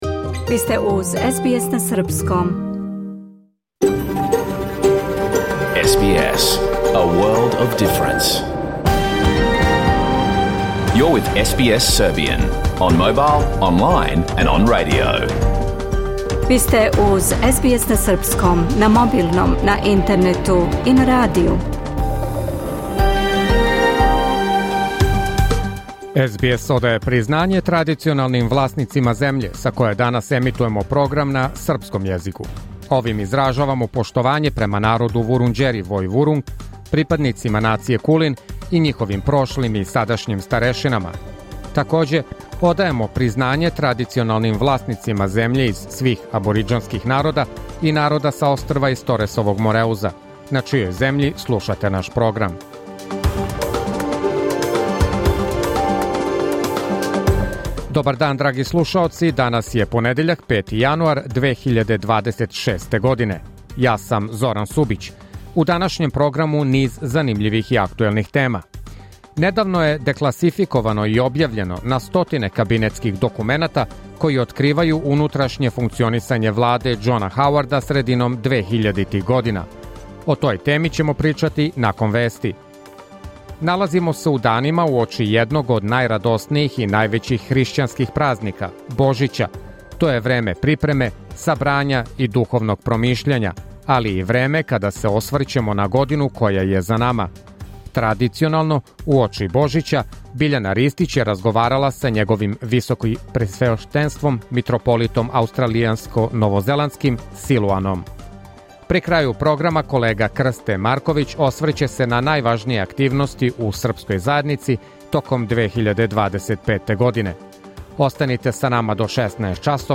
Програм емитован уживо 5. јануара 2026. године